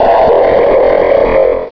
Fichier:Cri 0365 DP.ogg — Poképédia
Cri_0365_DP.ogg